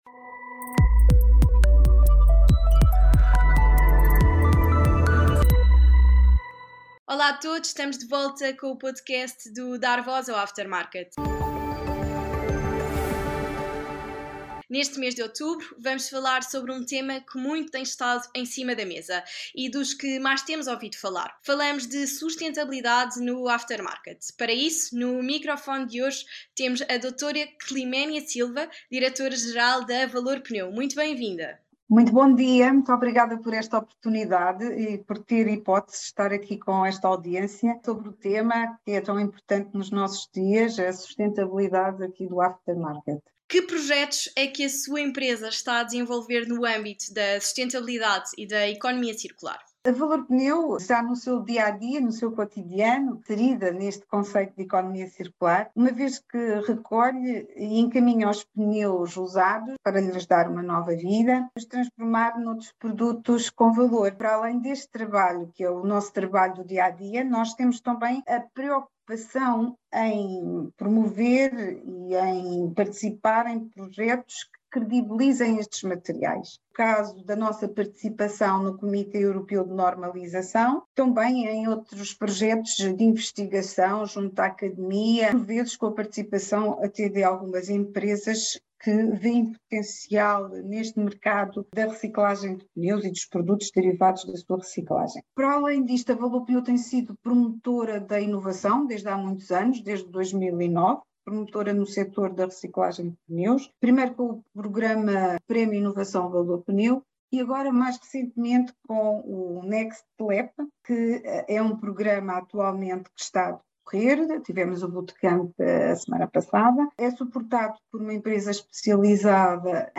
A sustentabilidade está na ordem do dia e é um tema-chave para a competitividade das empresas, cada vez mais importante para as suas estratégias de curto, médio e longo prazo. Por este motivo, no Podcast de outubro vamos falar de “Sustentabilidade no Aftermarket”.
we had a conversation